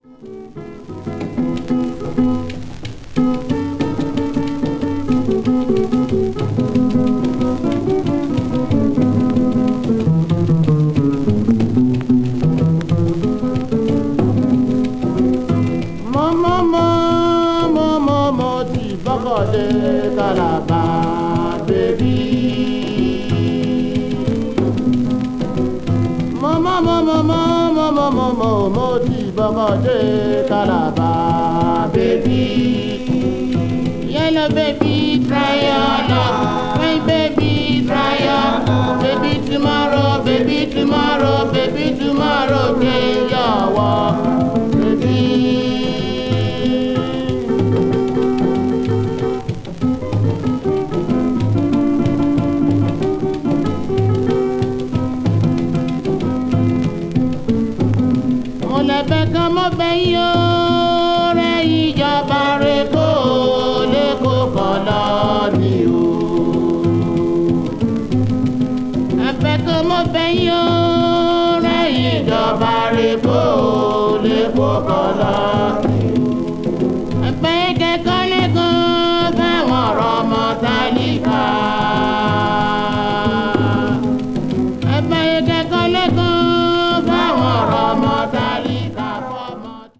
バックグラウンド・ノイズはSP盤に起因するものです。
africa   classical   traditional   world music